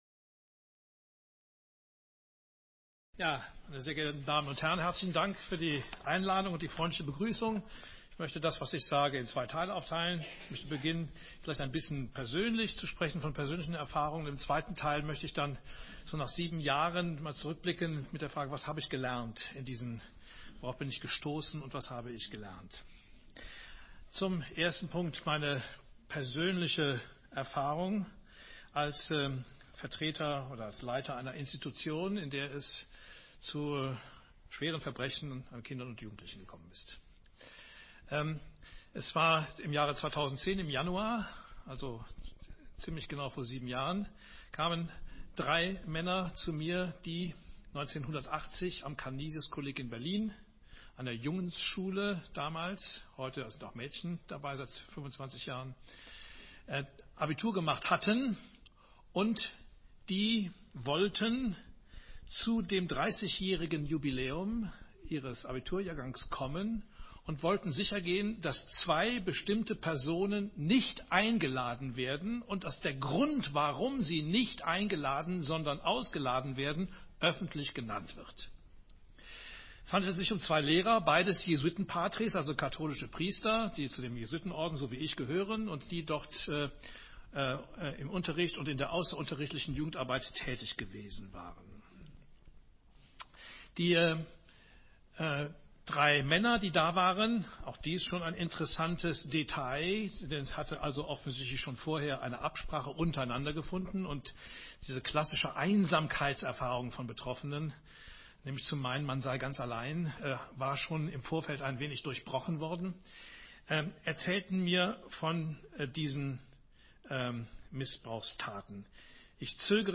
In persönlichen Worten schilderte Mertes vor dem Korntaler Publikum seine eigene Betroffenheit in dieser Situation.